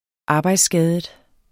Udtale [ ˈɑːbɑjdsˌsgæːðəd ]